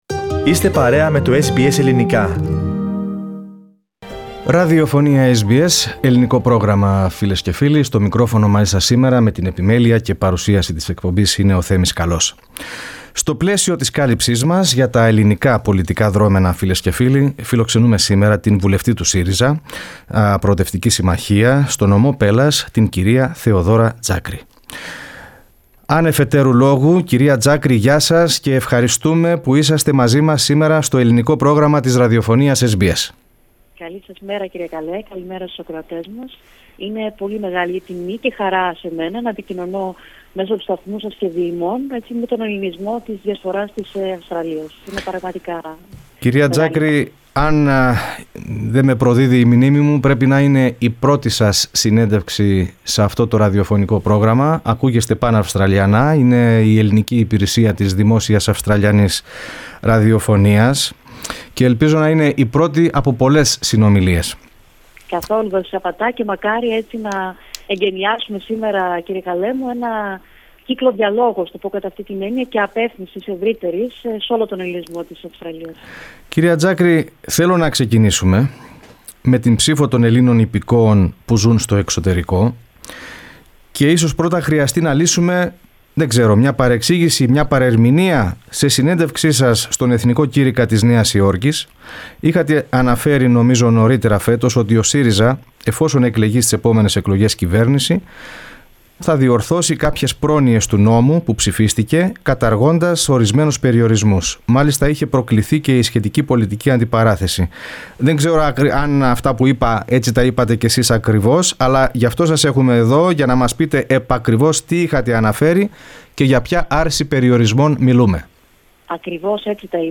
Σε μια εφ’ όλης της ύλης συνέντευξή της, η βουλευτής ΣΥΡΙΖΑ στον Νομό Πέλλας, Θεοδώρα Τζάκρη κλήθηκε μεταξύ άλλων να απαντήσει για τις δηλώσεις της προ μηνών στον ‘Εθνικό Κήρυκα’ της Νέας Υόρκης και την πολιτική αντιπαράθεση που ακολούθησε σχετικά με την ψήφο ομογενών.